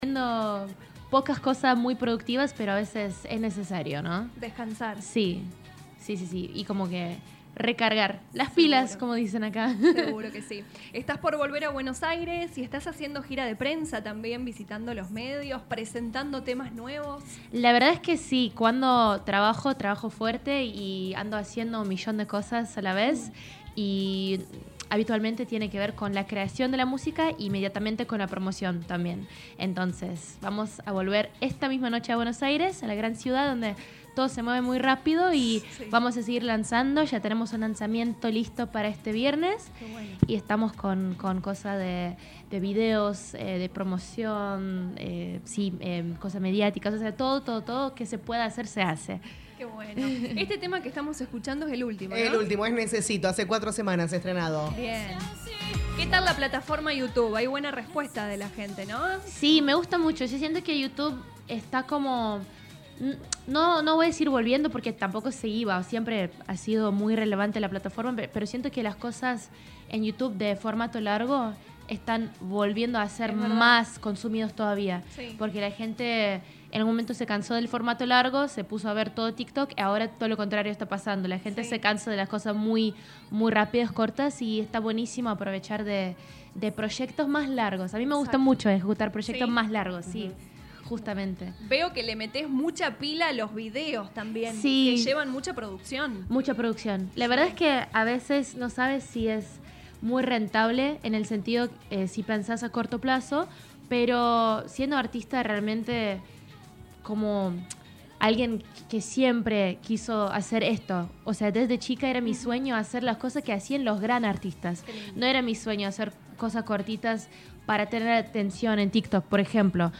visitó los estudios de LA RADIO 102.9 FM, habló de su presente musical